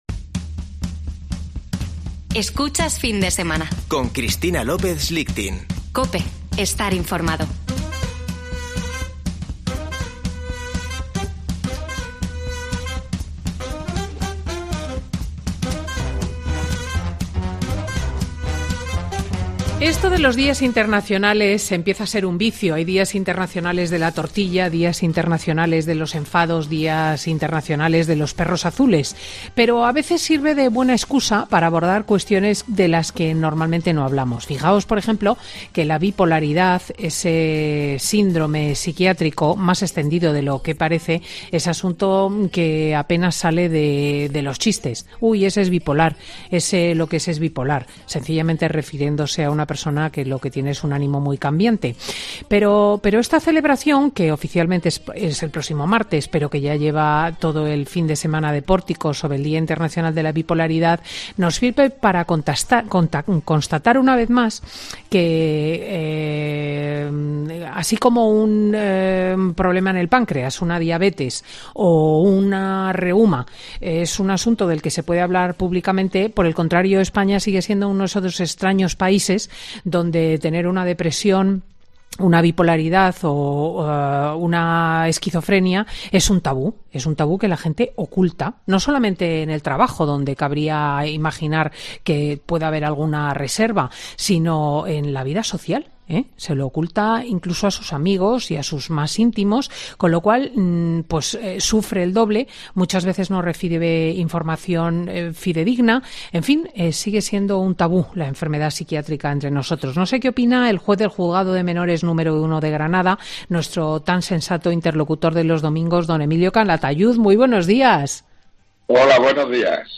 Don Emilio Calatayud explica en Fin de Semana con Cristina cómo puede afectar algún desvío en la mente a las personas que